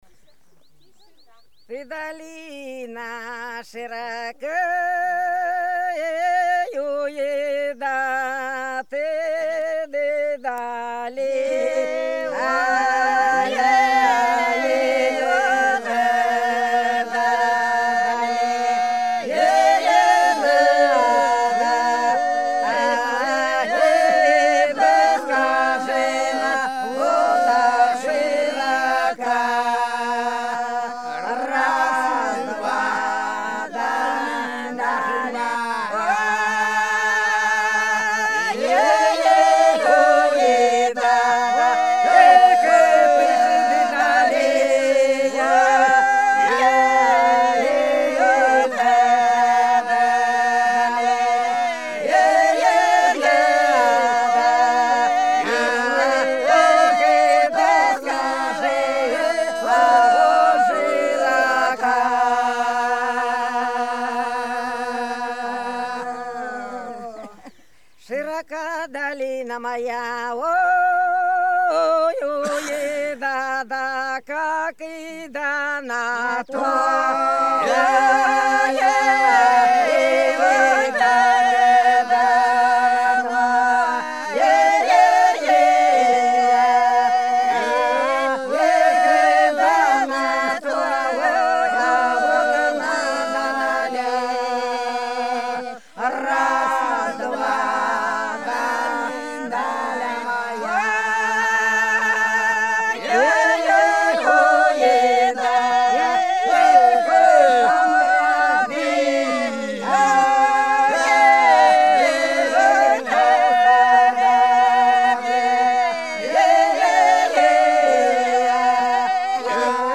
Белгородские поля (Поют народные исполнители села Прудки Красногвардейского района Белгородской области) Ты, долина, широка - протяжная